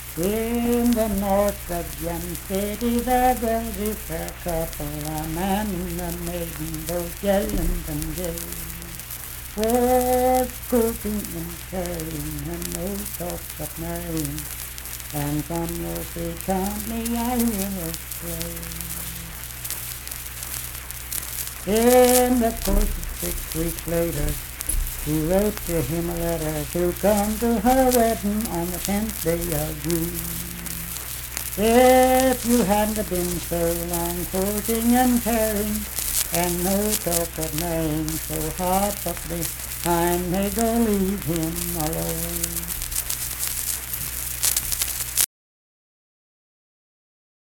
Unaccompanied vocal music performance
Verse-refrain 4(2).
Voice (sung)